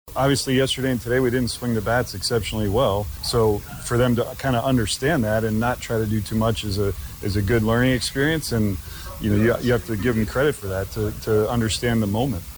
Royals manager Matt Quatraro on the offense coming alive late.
8-27-Royals-manager-Matt-Quatraro-on-the-offense-coming-alive-late.mp3